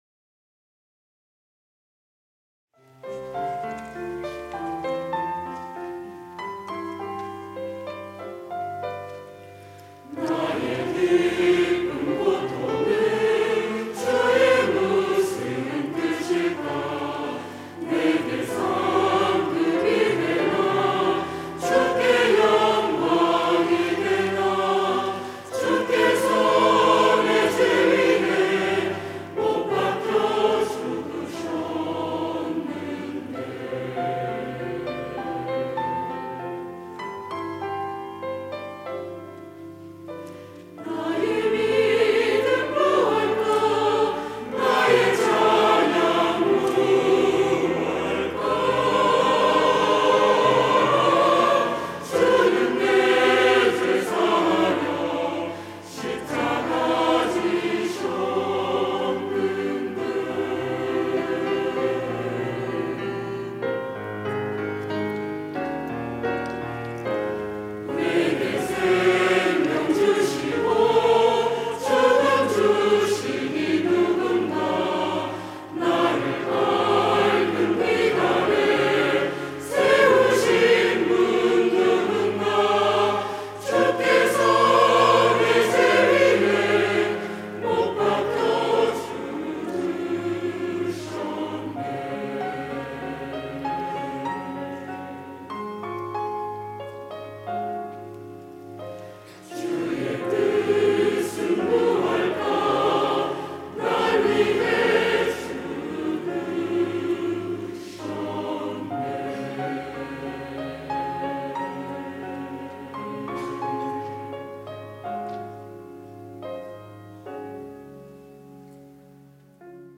찬양대 시온